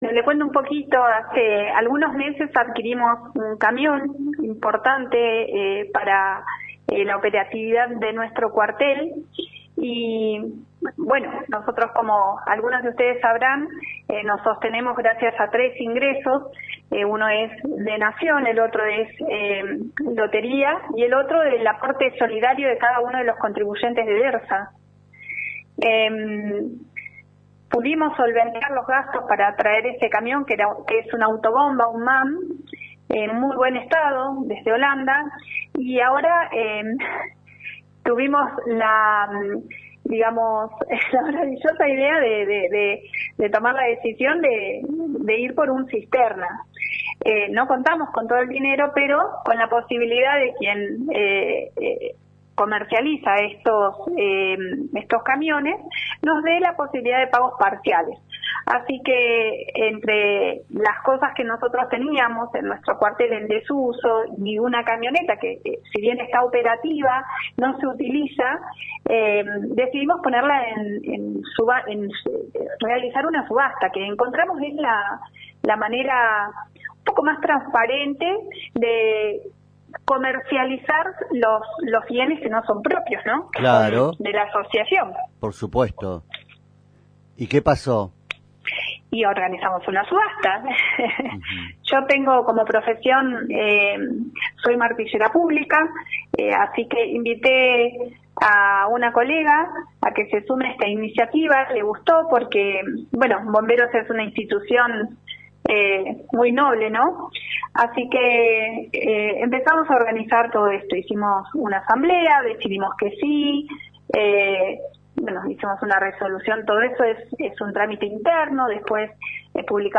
En diálogo con la radio explicó por qué se resolvió llevar adelante esta subasta solidaria.